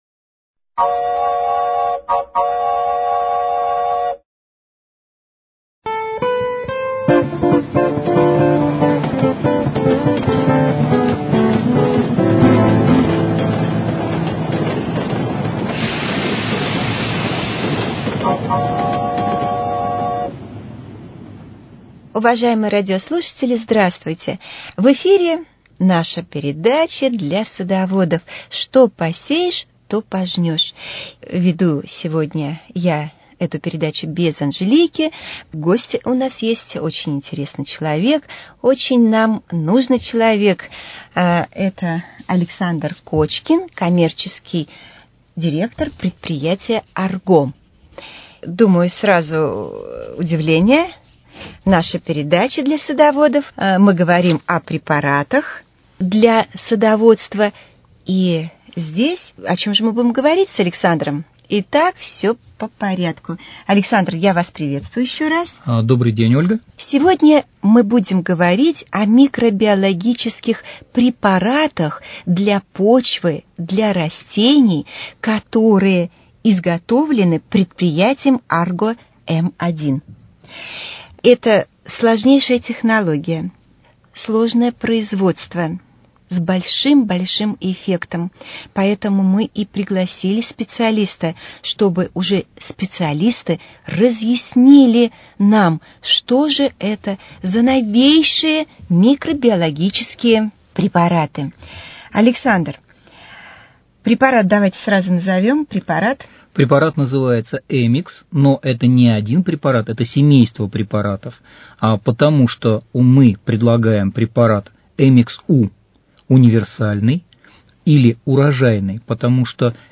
Что посеешь, то пожнешь: Беседа